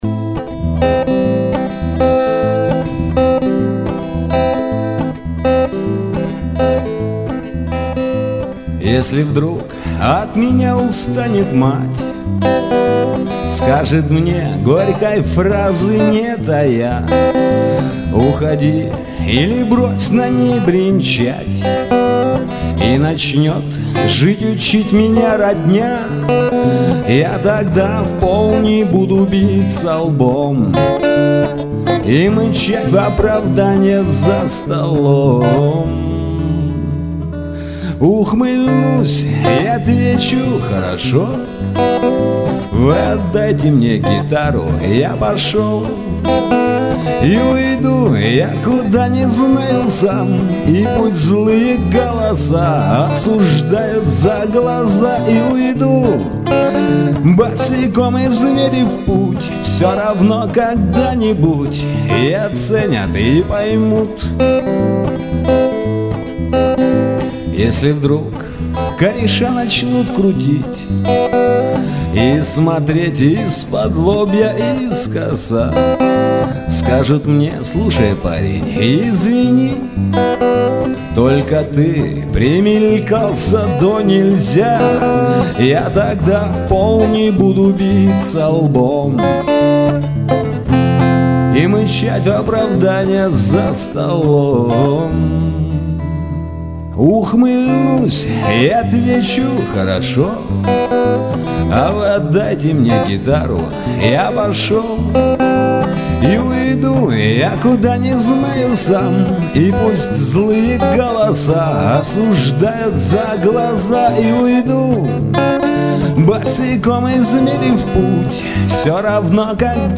Несколько песен одного из участников концерта "О чем поют мужчины".